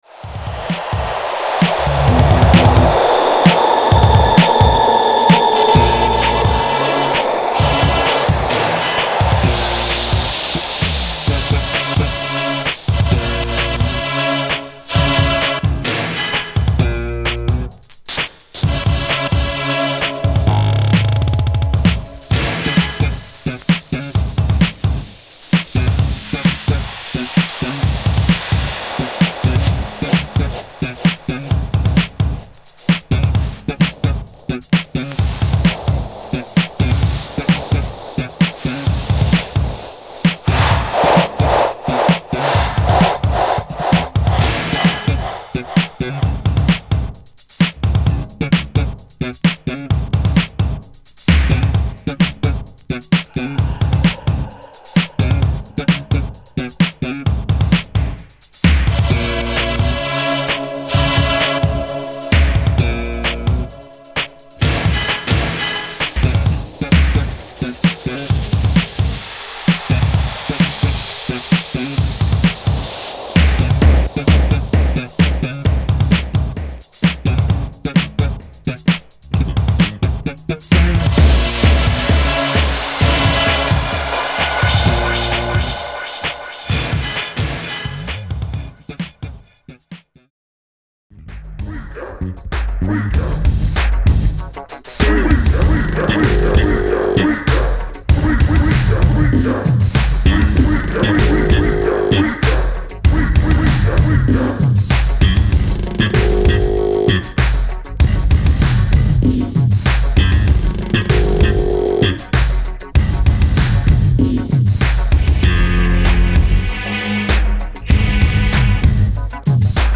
４５rpmなんだけど、３３rpmで試聴作ってあります。